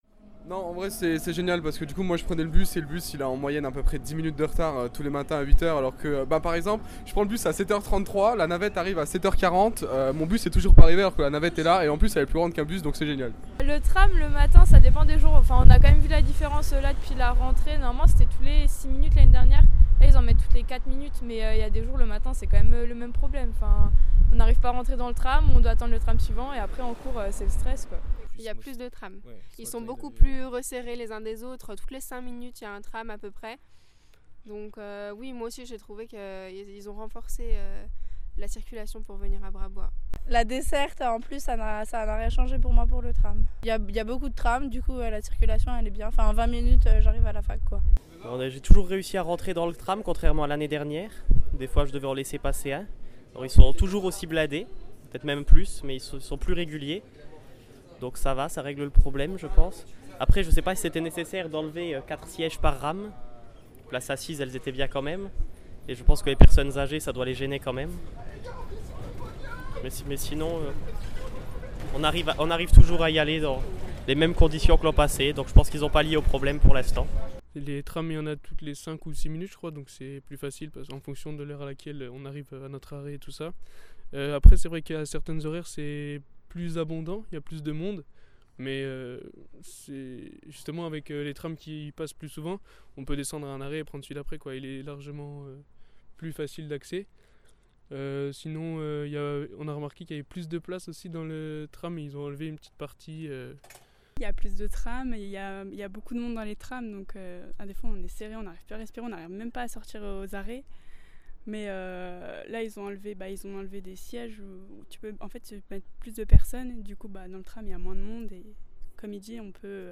Radio campus Lorraine s’est rendu aujourd’hui sur le campus Brabois pour avoir les premiers avis des étudiants.